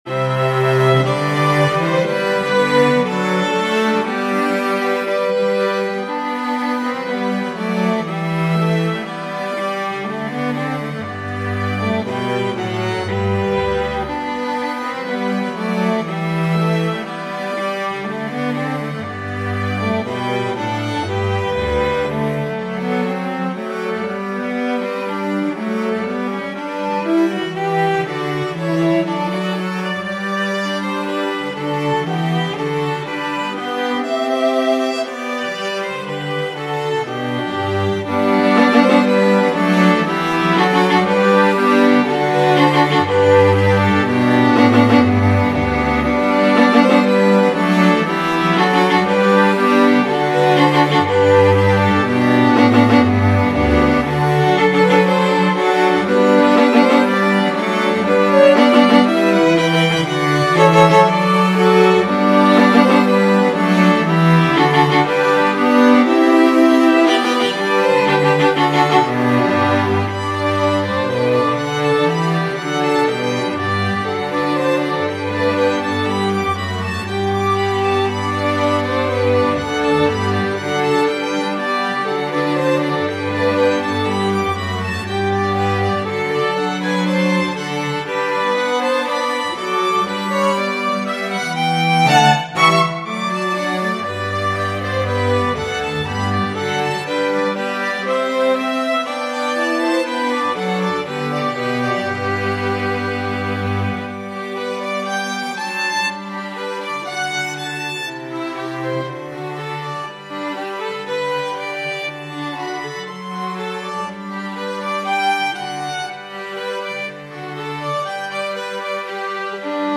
String Quartet